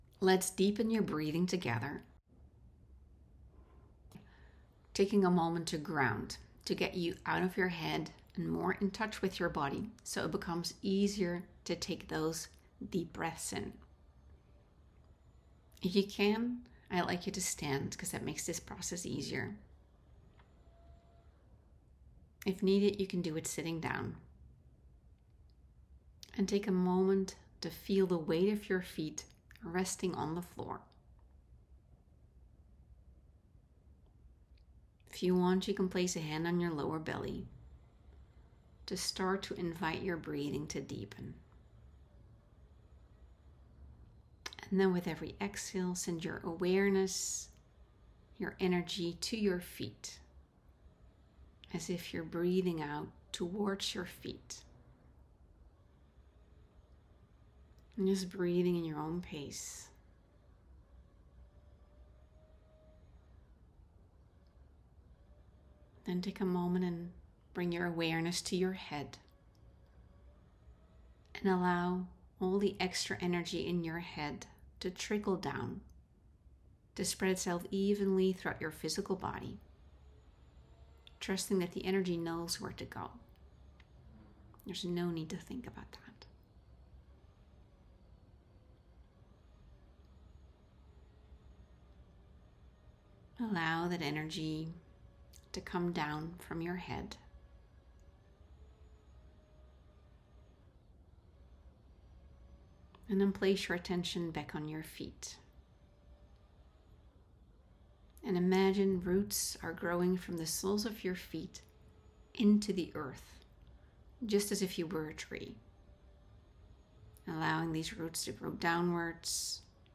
I’ve created this 4.5 minute guided audio to help you deepen your breathing:
Deep-breathing.m4a